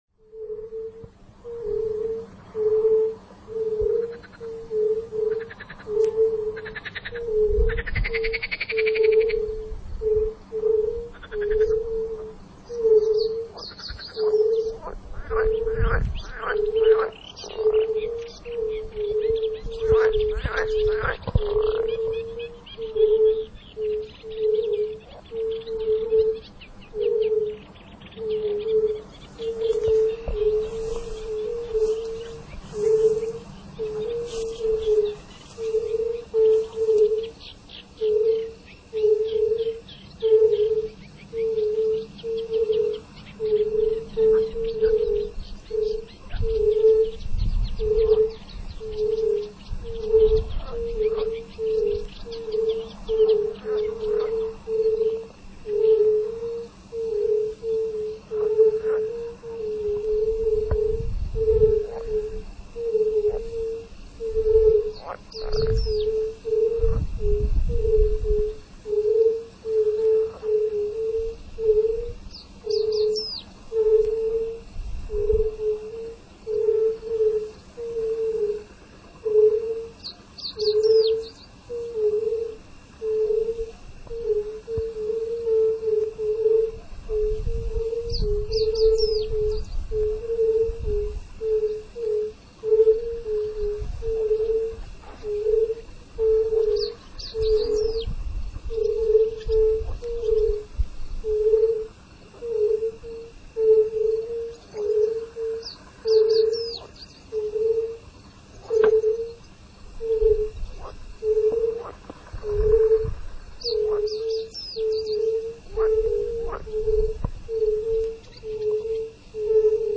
Zebrane z najpi�kniejszych zak�tk�w Polski (Bory Tucholskie, Zalew Koronowski, Jeziora Mazurskie oraz inne ciekawe miejsca). Wszystkie zarejestrowane d�wi�ki nie s� w �aden spos�b modyfikowane syntetycznie. Jedyn� zastosowan� obr�bk� by�o odszumianie i eliminowanie przesterowa� wynikaj�cych z nat�enia d�wi�ku.
Ten rodzaj pozwala na uzyskanie najbardziej naturalnych klimat�w jakie spotka� mo�na w lesie na relaksuj�cym spacerze.
659-symfonie_natury_odglosy_lasu.mp3